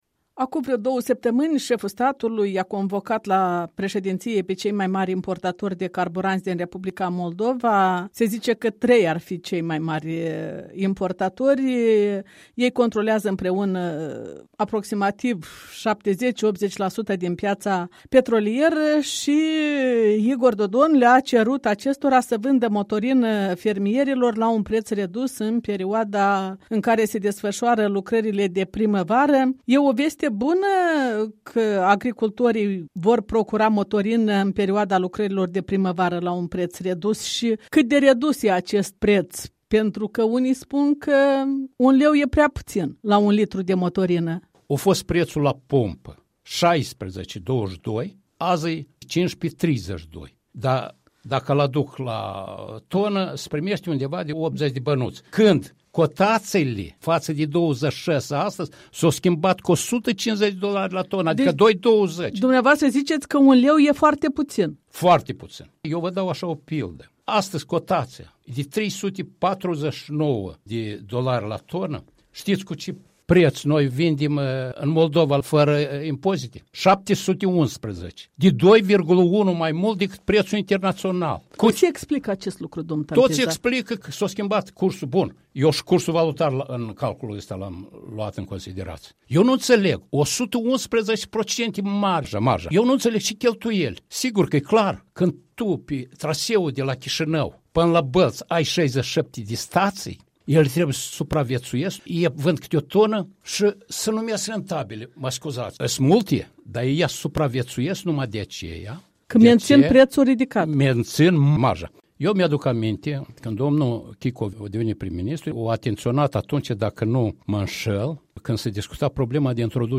Interviu cu fostul ministru al economiei, ex-director al „Lukoil România”, în prezent fermier.
Interviu cu fostul director general al „Lukoil România”, Constantin Tampiza